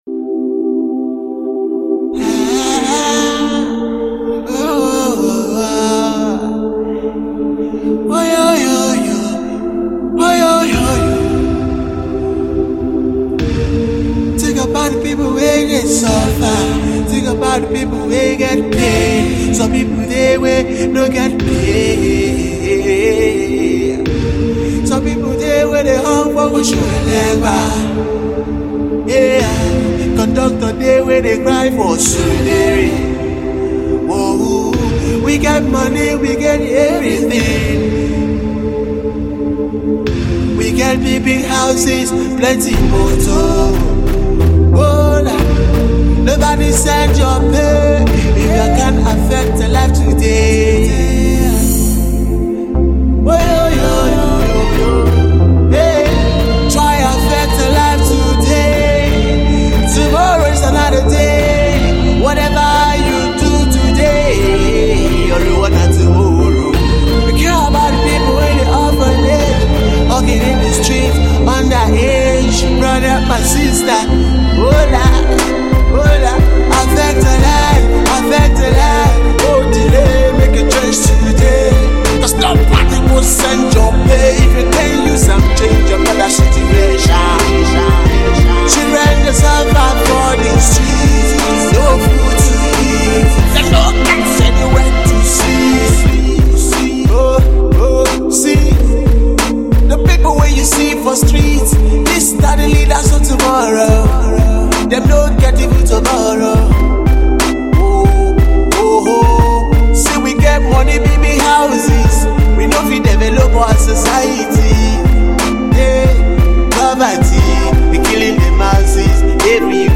Ballad, Inspirational …